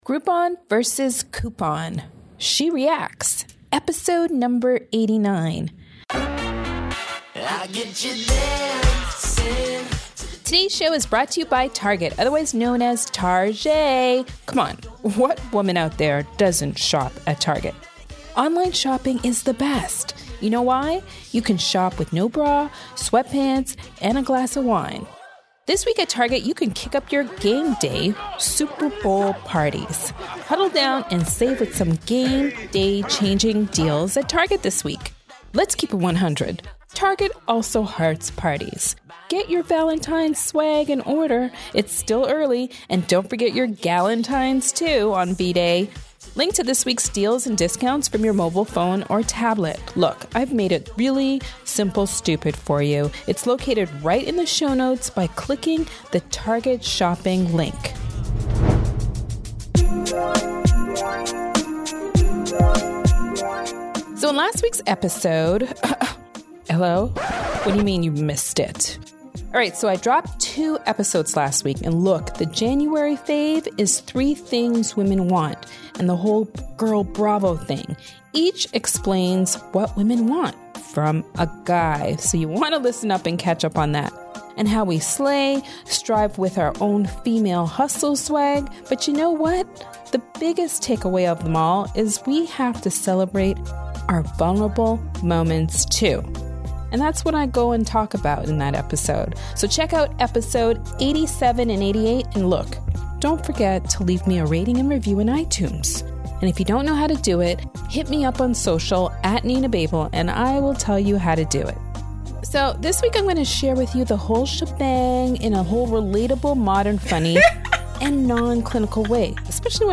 So you will hear us go back and forth with our arguments.